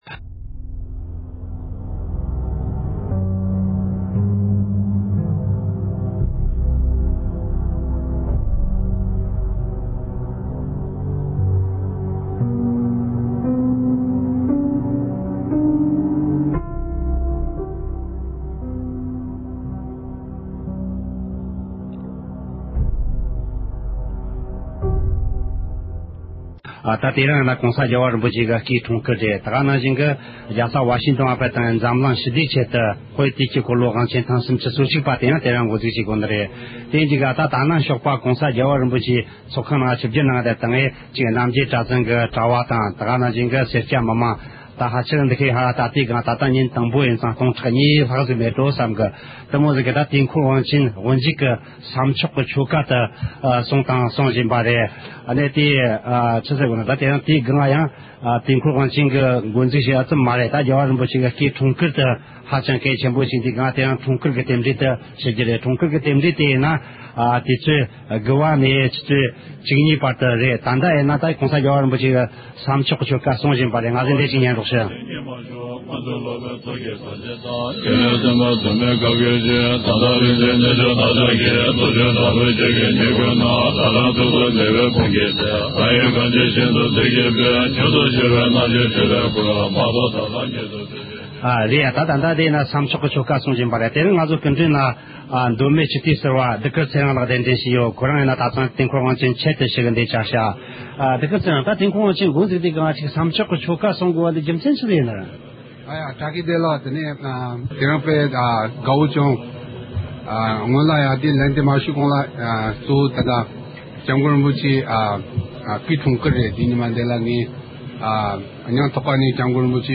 ཨ་རིའི་རྒྱལ་ས་ཝ་ཤིན་ཊོན་གི་ Verizon Center ཞེས་པའི་ཚོགས་ཁང་ཆེན་མོའི་ནང།